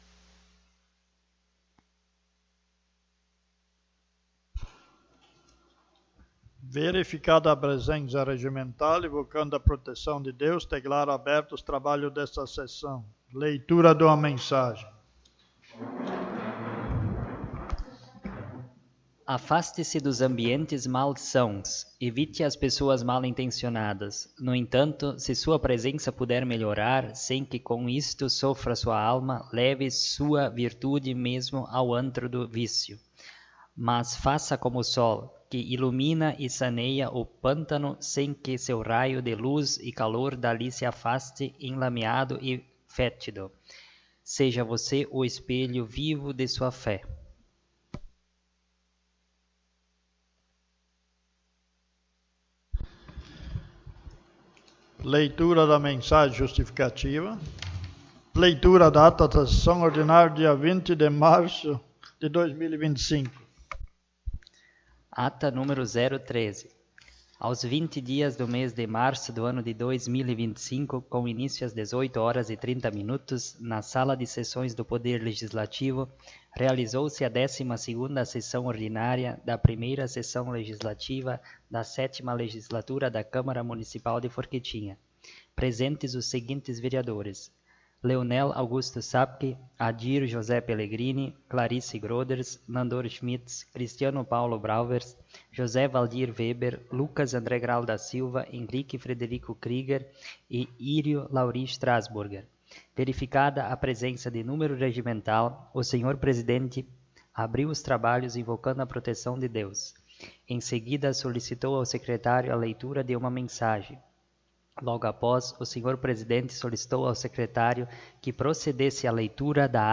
13ª Sessão Ordinária - Câmara de Vereadores de Forquetinha
Vereadores presentes